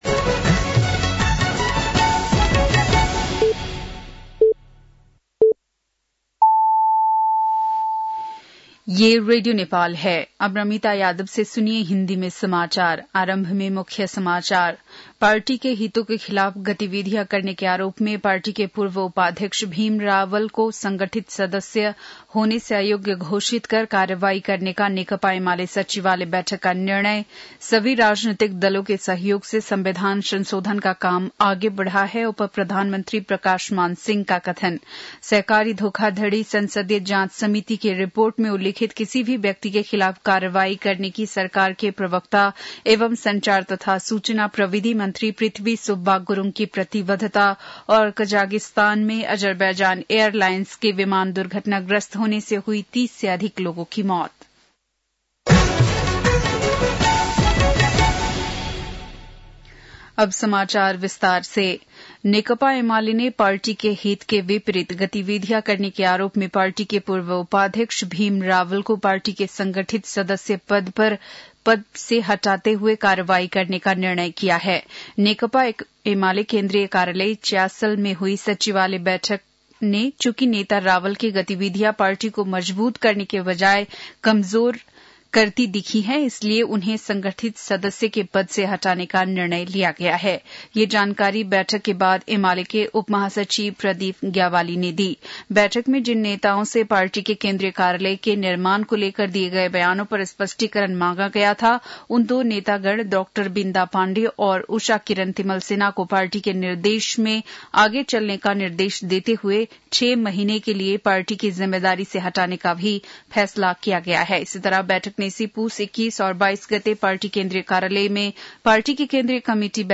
बेलुकी १० बजेको हिन्दी समाचार : ११ पुष , २०८१